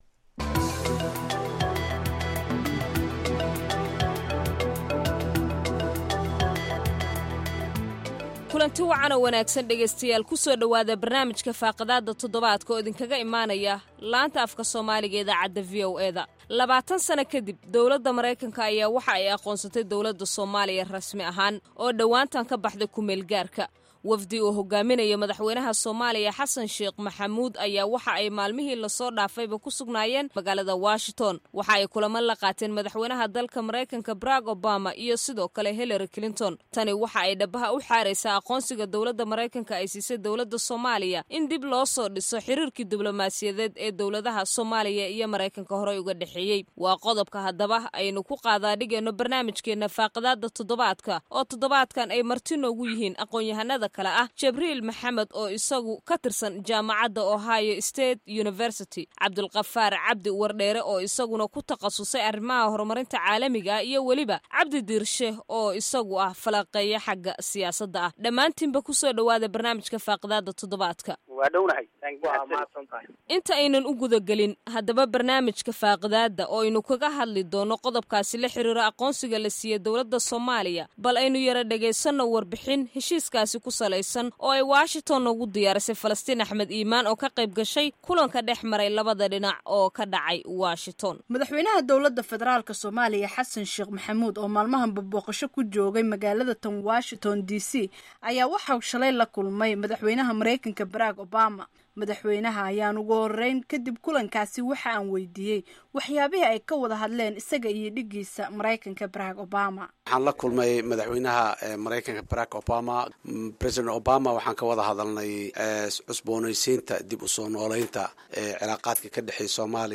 Barnaamijka Faaqidaadda Todobaadka waxaa marti ku ah aqoonyahanno Soomaaliyeed oo falanqeynaya waxa uu ka dhiganyahay aqoonisga Mareykanka ee dowladda Soomaaliya iyo fursaddaan sida looga faaiideysan karo.